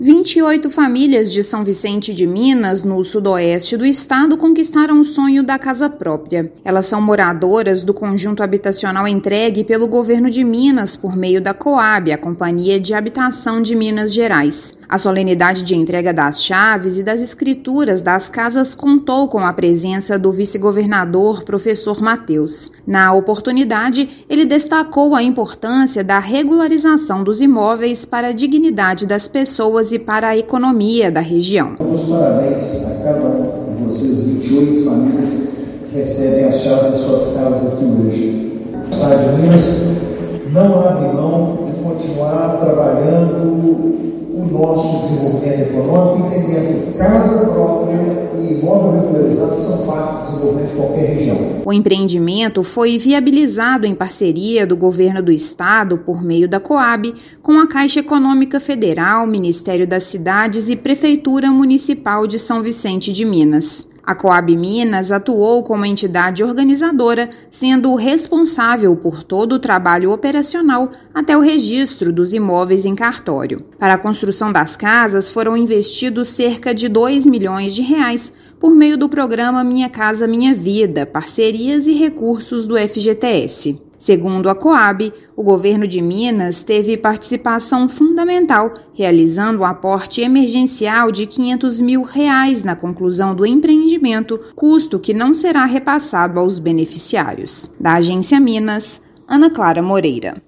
Cohab Minas atuou para viabilizar a construção e entrega das 28 casas do Conjunto Habitacional Prefeito Paulo Roberto de Oliveira Gonçalves III. Ouça matéria de rádio.